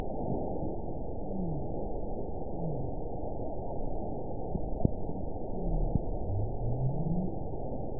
event 922087 date 12/26/24 time 09:38:49 GMT (11 months, 1 week ago) score 9.47 location TSS-AB04 detected by nrw target species NRW annotations +NRW Spectrogram: Frequency (kHz) vs. Time (s) audio not available .wav